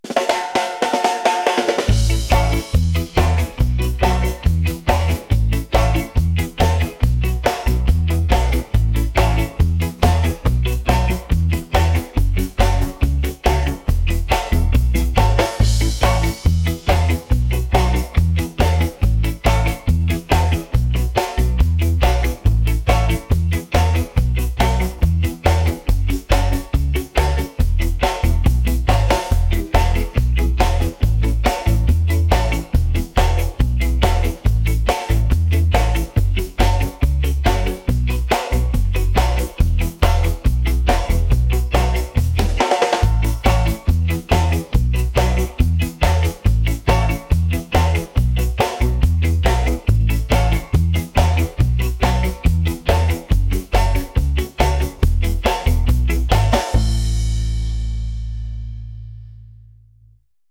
catchy | reggae | upbeat